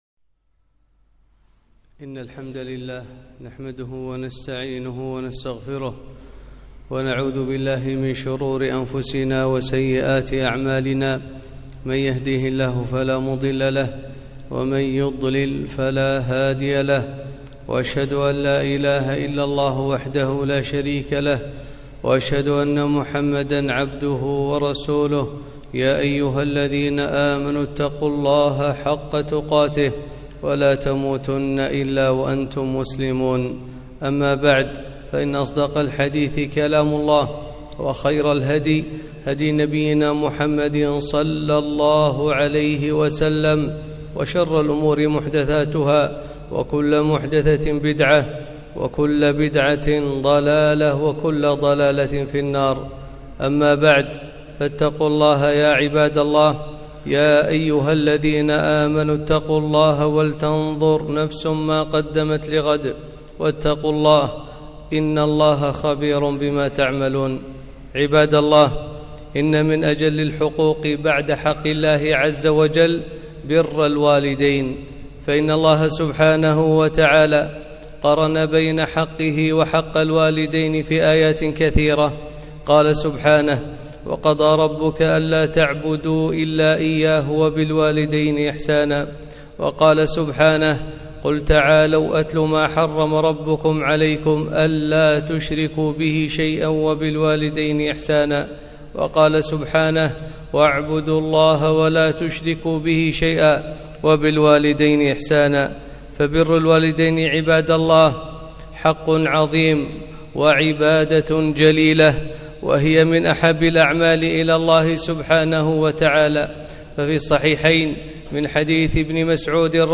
خطبة - بر الوالدين